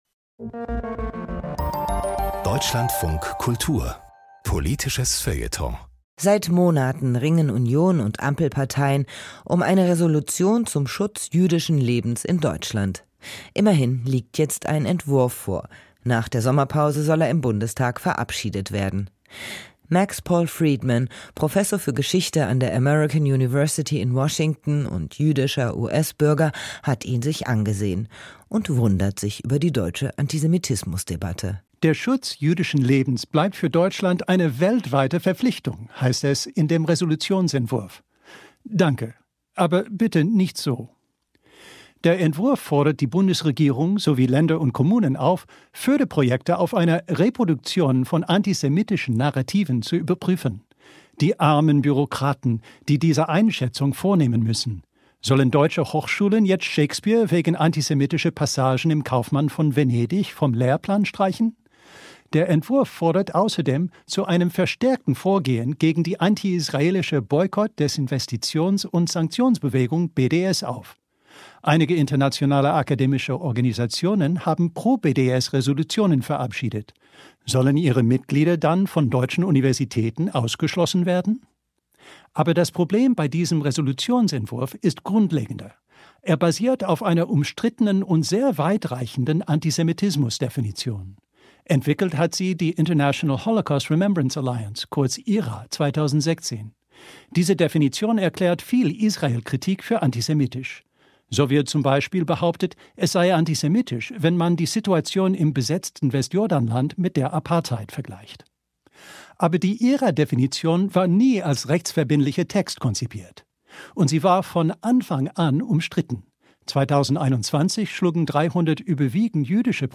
Kommentar - Eine Resolution wird das jüdische Leben nicht schützen